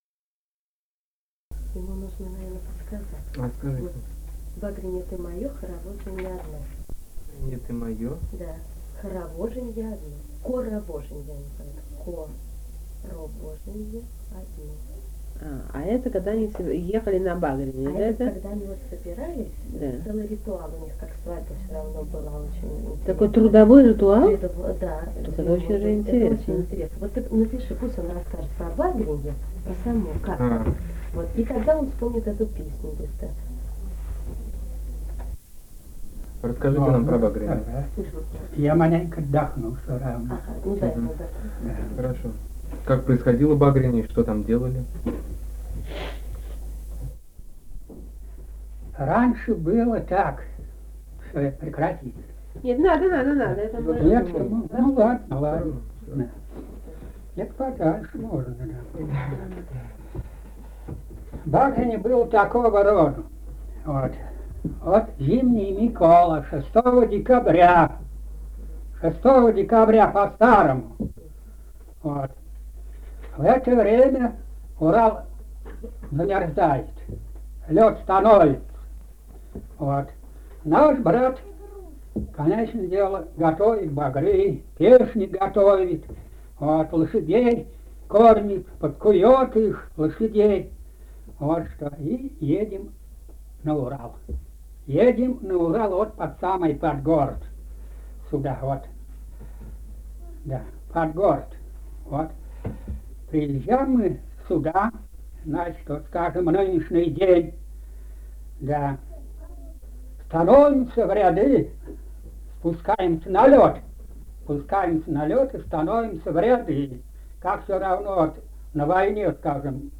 Казахстан, г. Уральск, 1972 г. И1312-11а